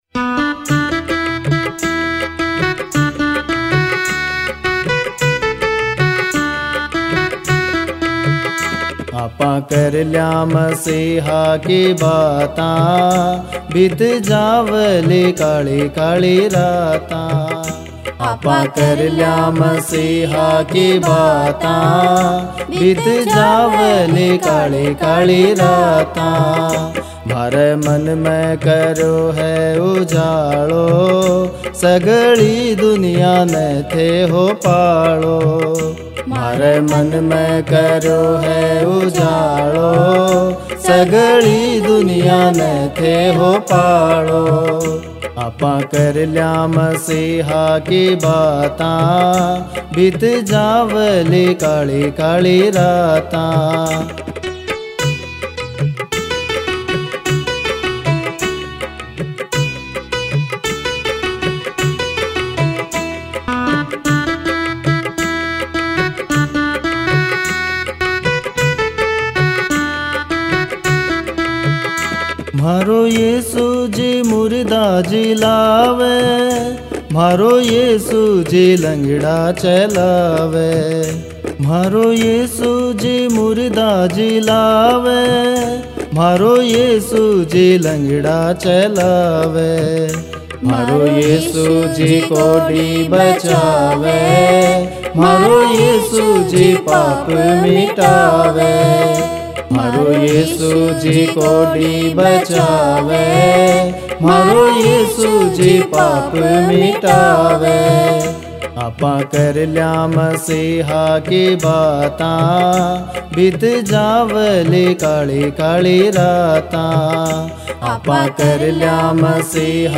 Shekhawati Christian Songs